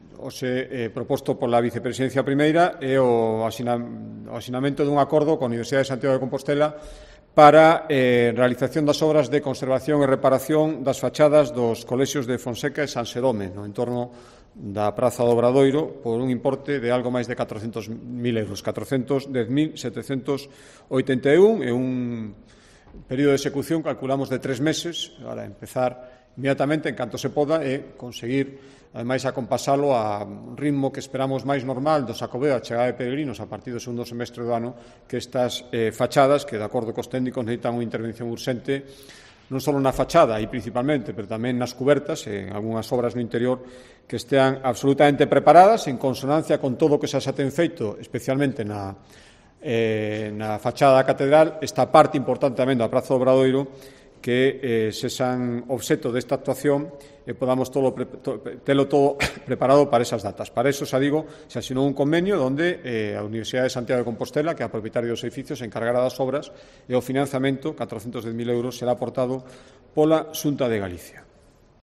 Alfonso Rueda explica la intervención en las fachadas de San Xerome y Fonseca